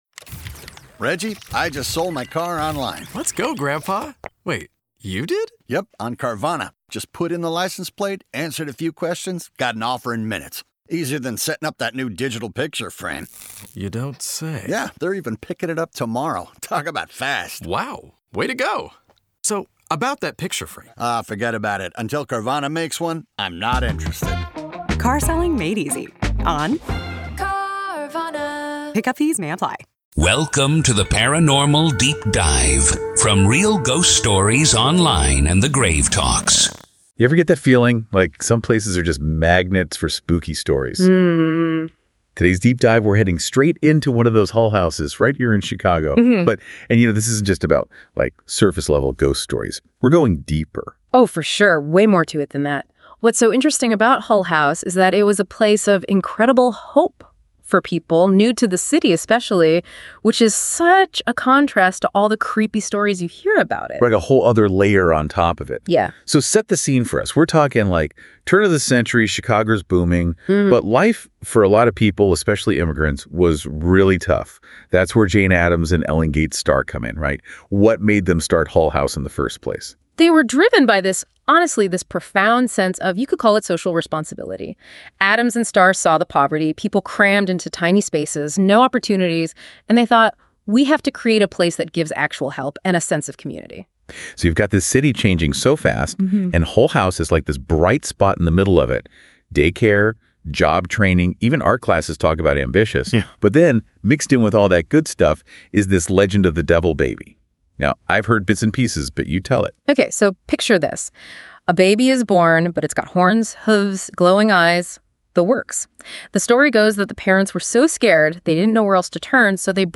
Deep Dive DISCUSSION!